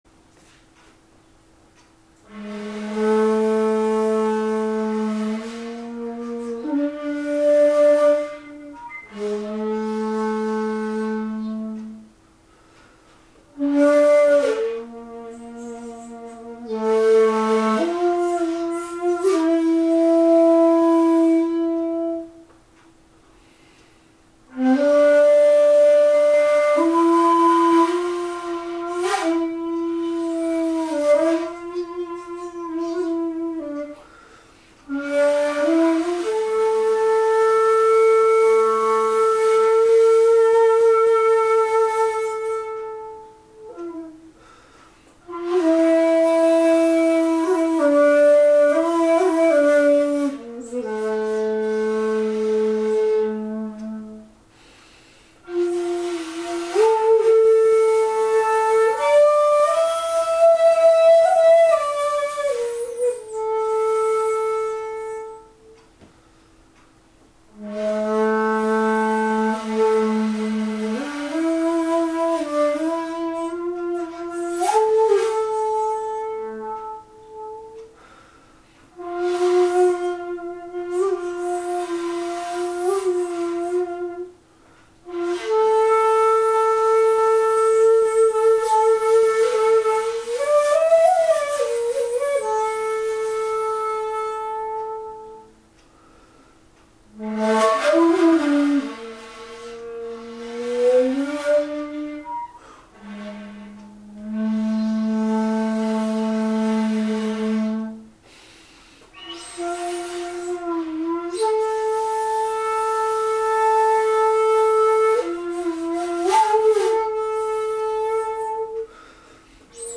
乞われるままに不慮の死を遂げた村人の弔いに般若心経を唱え尺八を吹けば「手向」という哀切きわまりない曲になります。
また地無し尺八での本曲録音も1つ付けています。ただし、状況により必ずしも現地での録音とは限りませんのでご了承下さい。